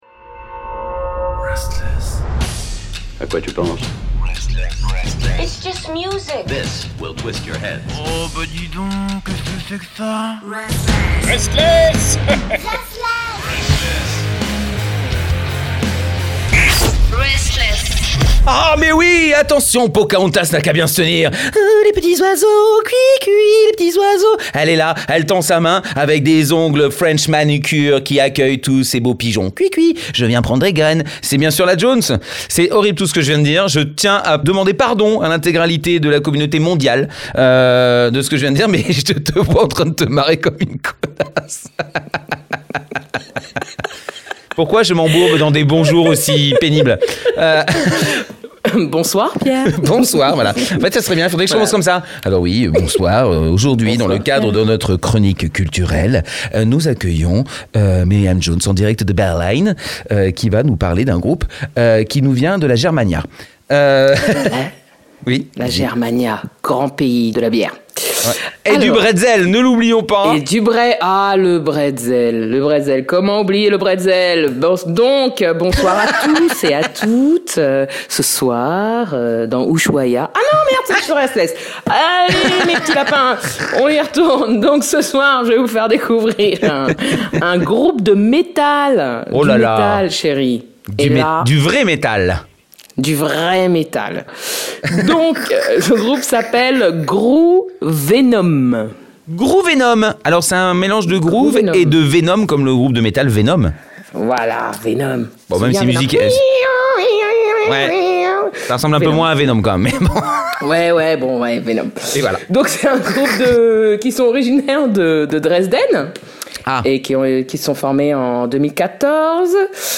c’est tous les mercredis à 19h sur RSTLSS Radio.